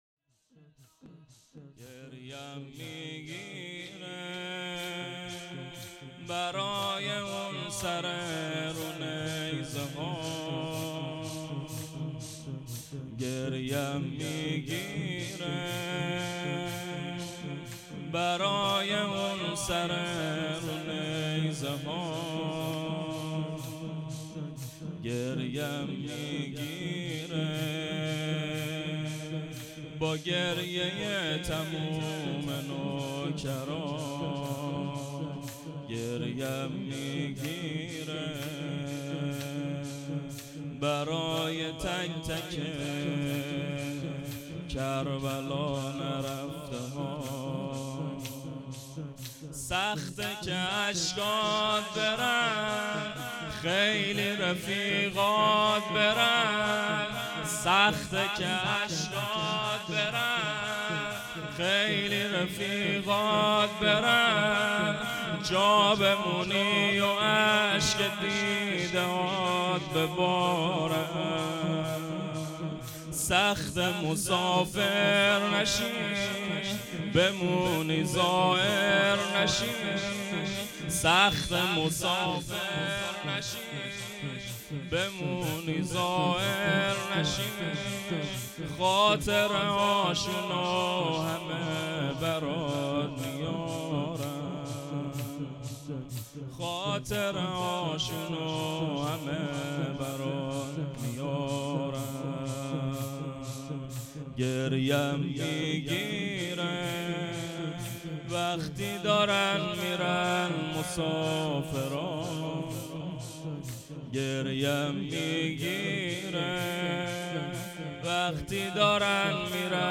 زمینه فراق کربلا